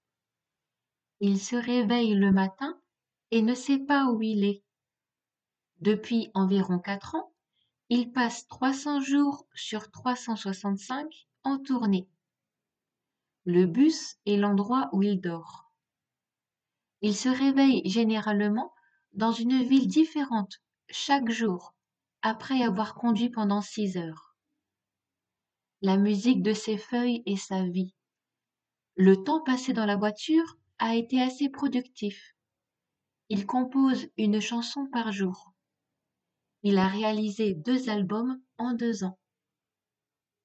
デイクテ
普通の速さで。